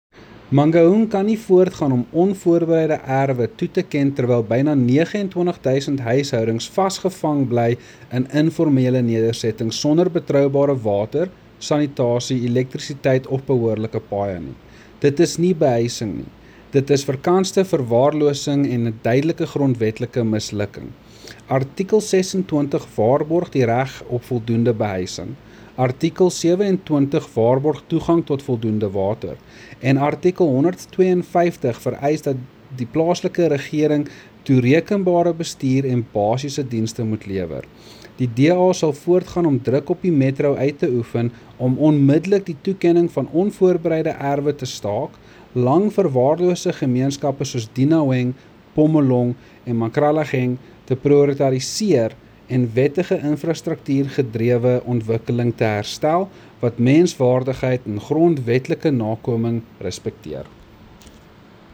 Afrikaans soundbites by Cllr Andre Snyman and